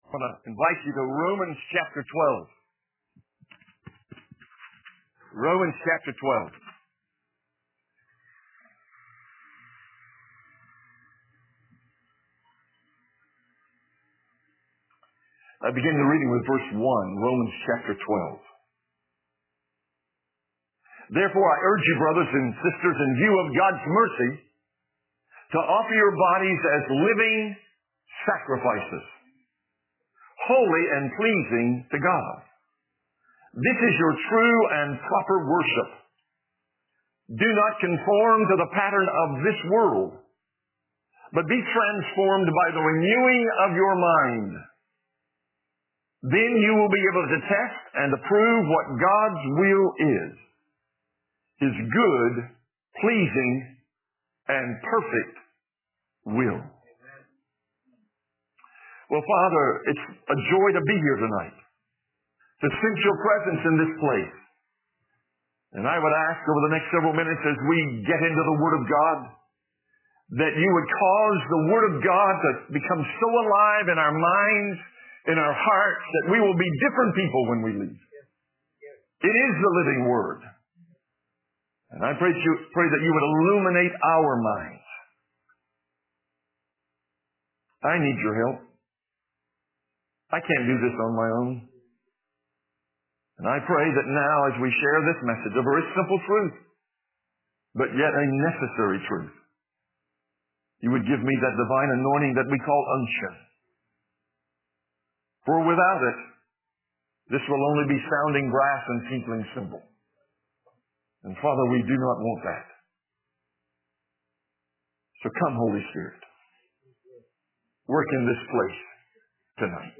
2018 Conference – Wed PM Message